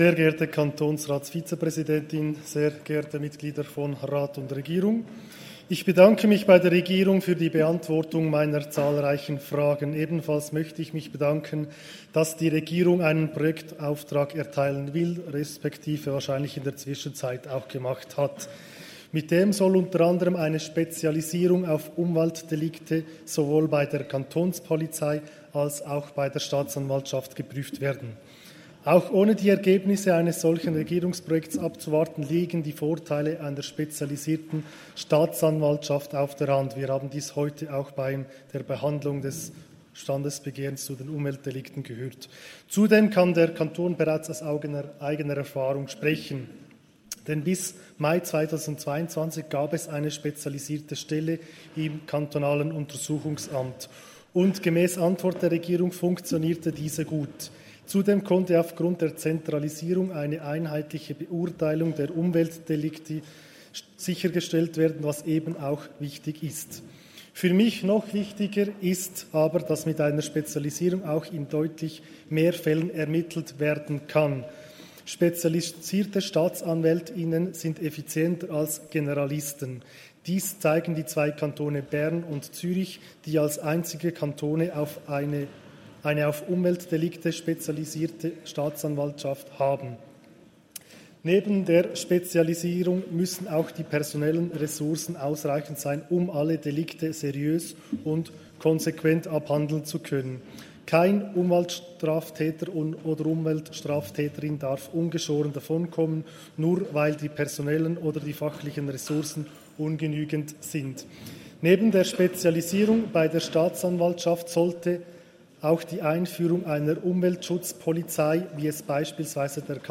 18.9.2023Wortmeldung
Session des Kantonsrates vom 18. bis 20. September 2023, Herbstsession